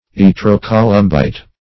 Search Result for " yttro-columbite" : The Collaborative International Dictionary of English v.0.48: Yttro-columbite \Yt`tro-co*lum"bite\, Yttro-tantalite \Yt`tro-tan"ta*lite\, n. (Min.) A tantalate of uranium, yttrium, and calcium, of a brown or black color.